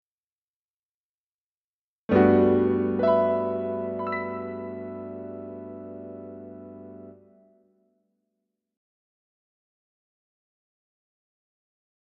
02 タイトルにいれ ｒ（略）、上がる感じ 00.12